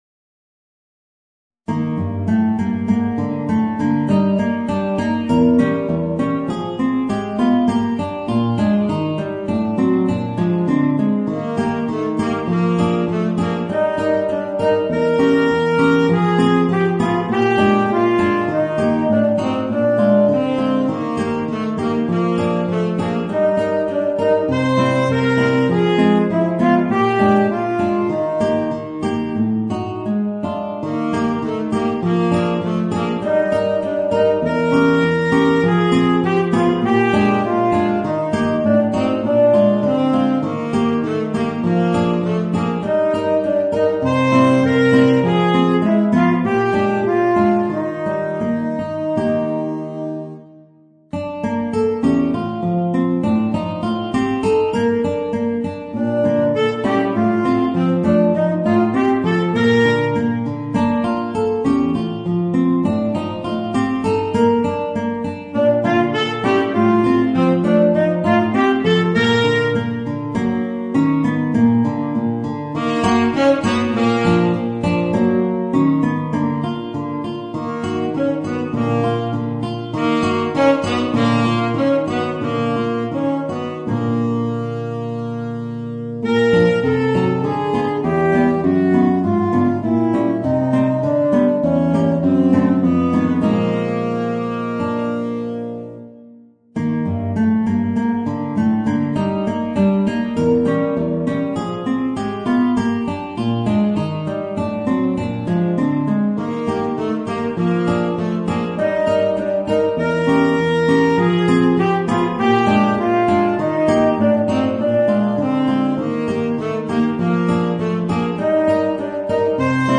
Voicing: Guitar and Tenor Saxophone